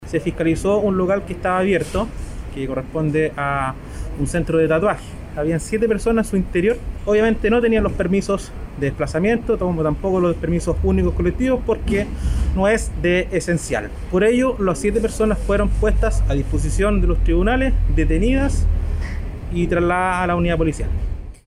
subcomisario de Servicios